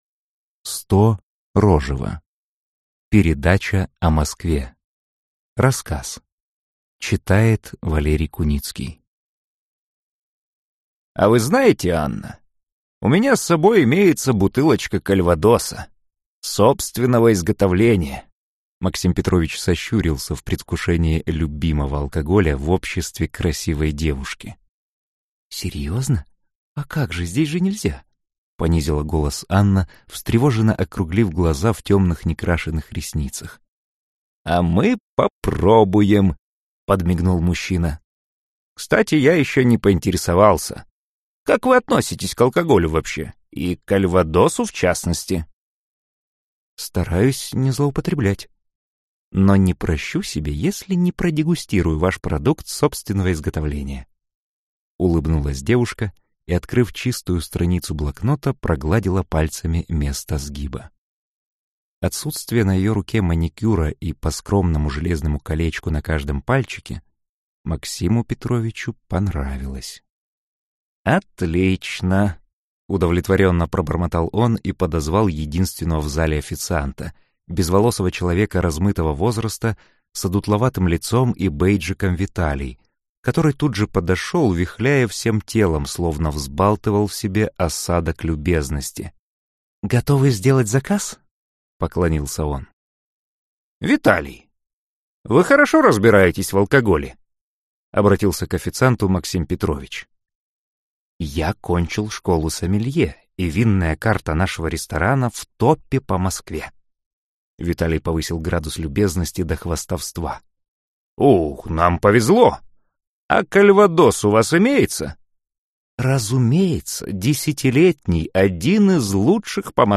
Аудиокнига Передача о Москве | Библиотека аудиокниг